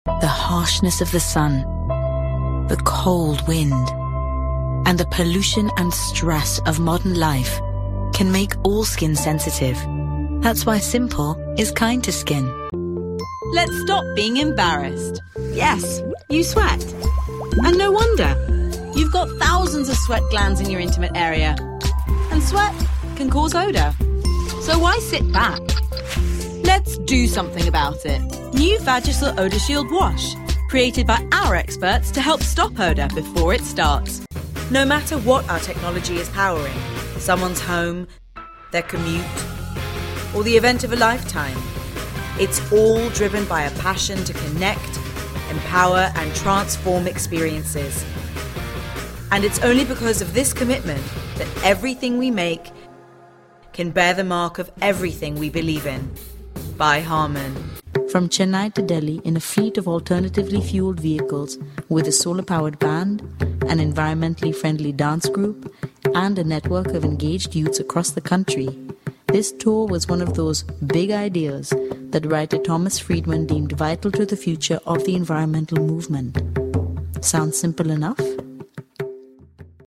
commercial : women